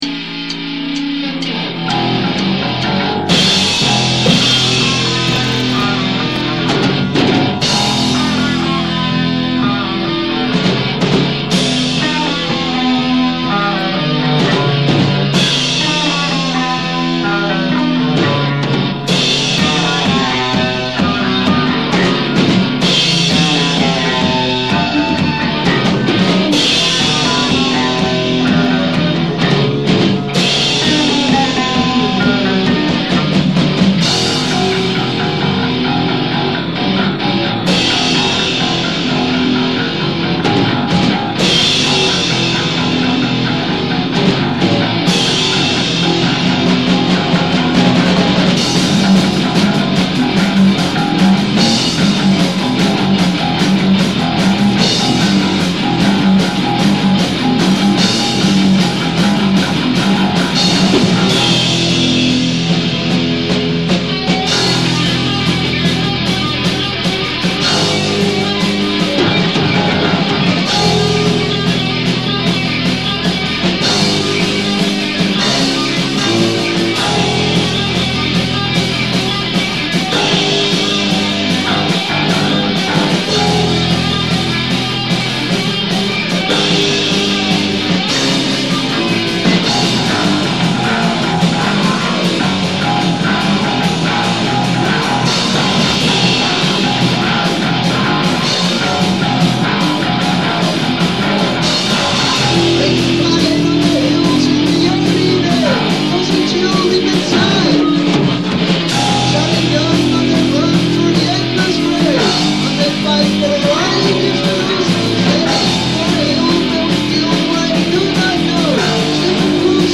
Onze eerste echte studio-opname! Ten behoeve van de eeuwfeest-CD van SSR-Leiden zijn we de studio ingedoken en het resultaat kan je hier downloaden (5170 KB, 192 kbit stream).